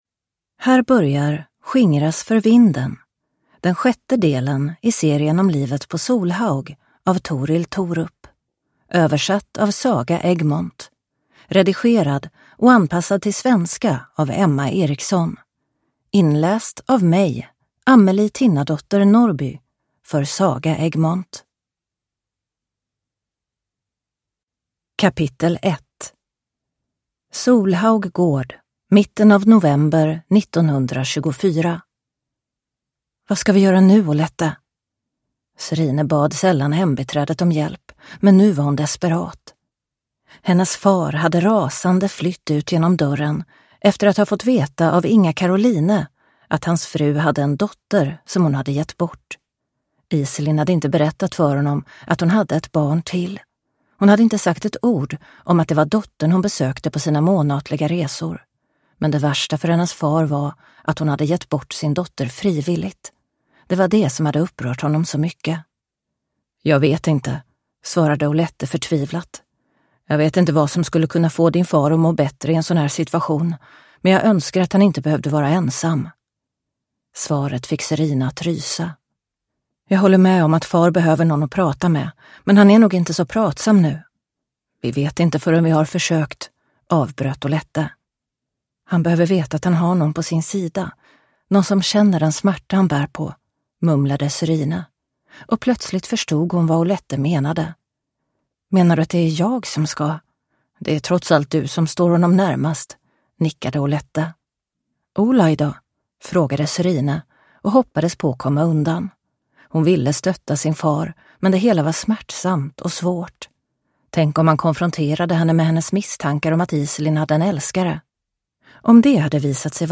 Skingras för vinden (ljudbok) av Torill Thorup